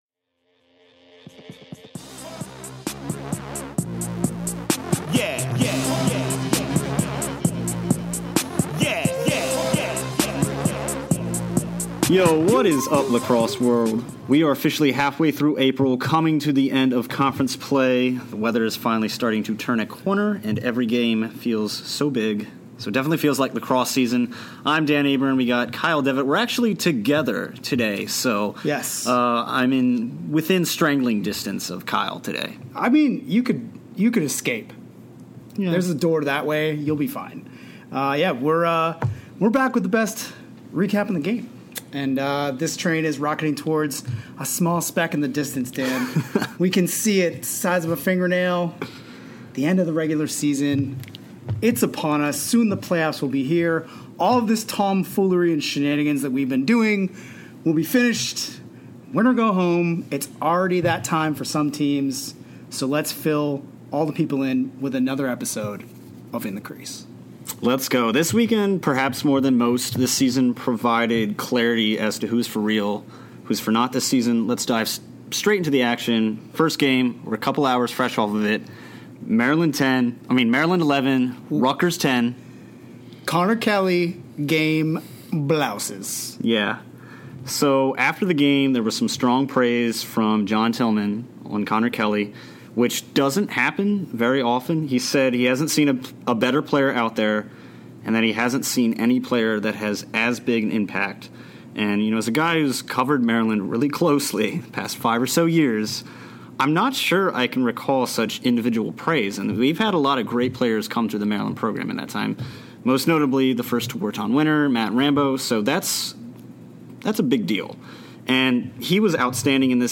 In a live streamed version of In The Crease